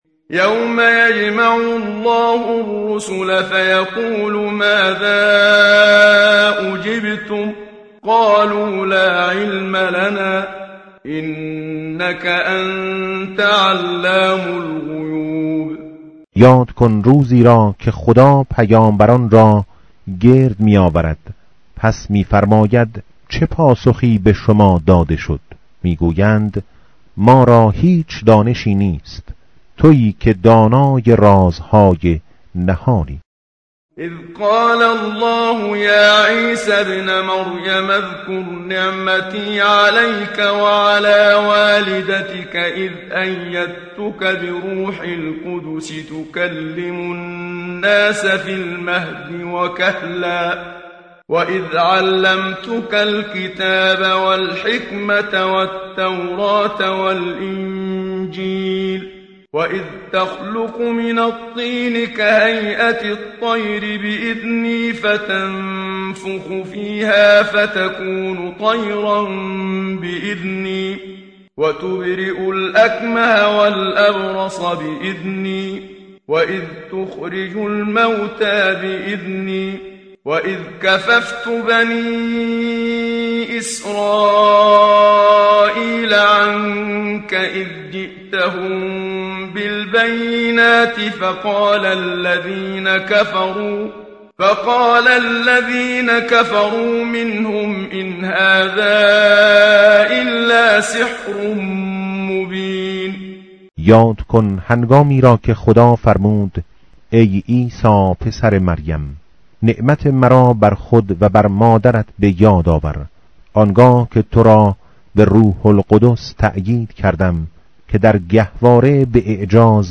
متن قرآن همراه باتلاوت قرآن و ترجمه
tartil_menshavi va tarjome_Page_126.mp3